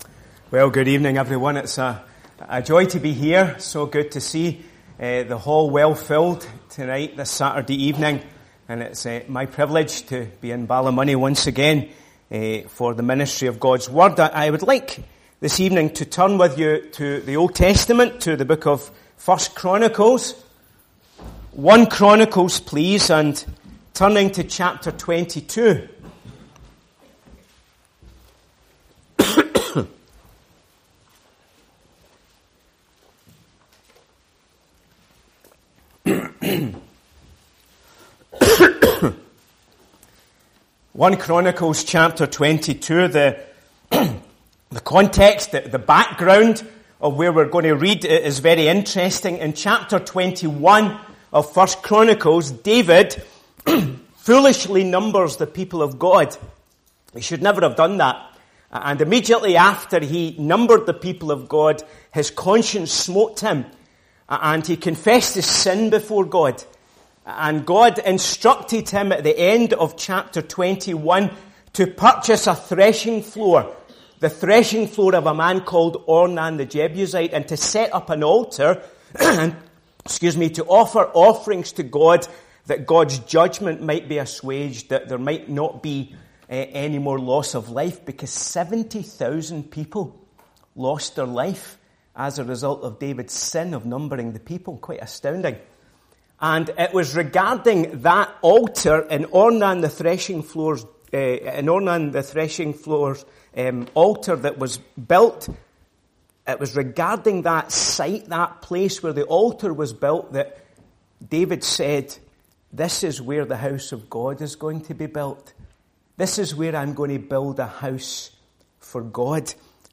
Passage: 1 Chronicles 22:1-19, Meeting Type: Ministry